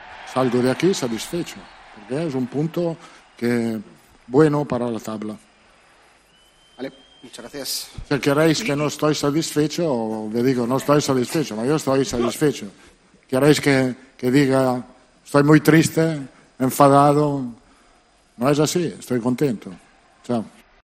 "No me quejo de nada ni de nadie, salgo de aquí satisfecho porque es un punto bueno para la tabla", comentó Ancelotti durante la rueda de prensa posterior al encuentro.